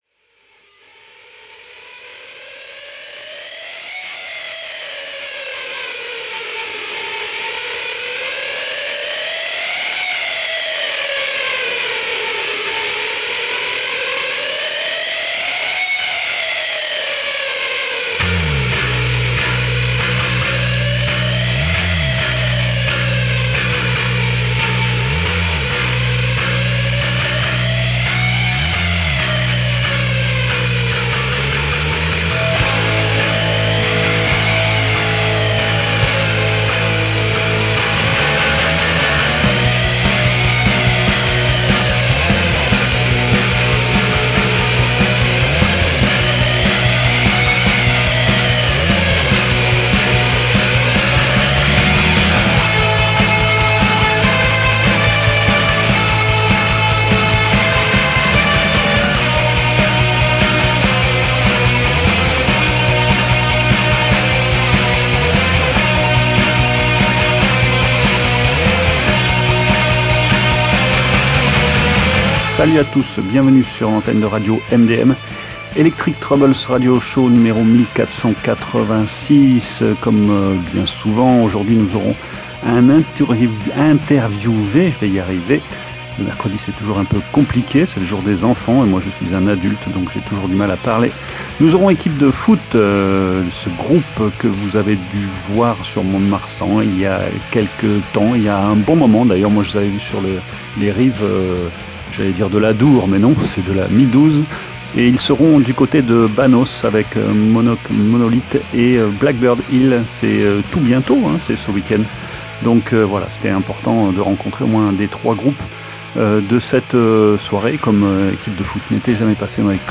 chant-guitare